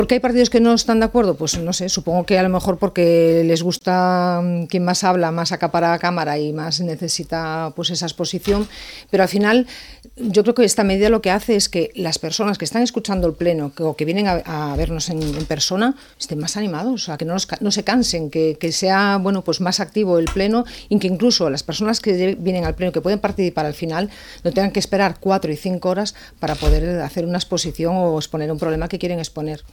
En una entrevista a Ràdio Calella TV, Coronil ha criticat que la mesura s’hagi aprovat “per decret” i sense debat polític, i alerta de l’impacte que pot tenir sobre els serveis municipals.